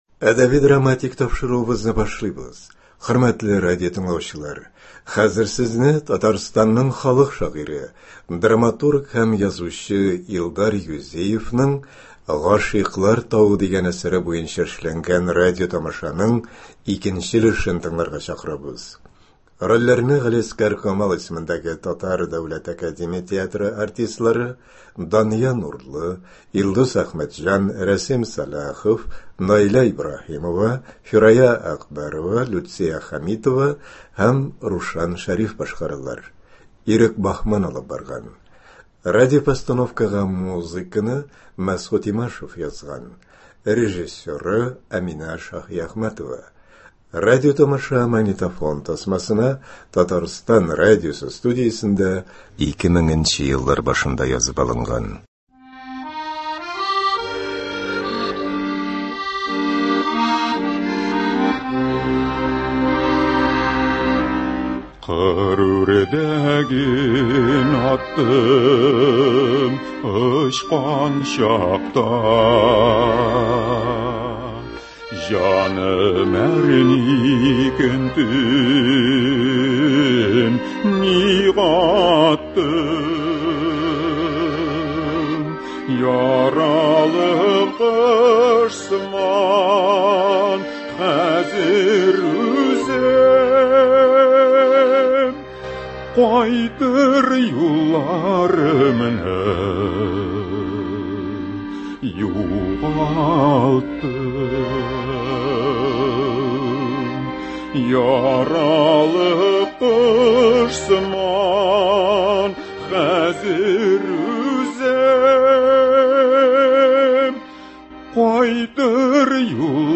Радиотамаша.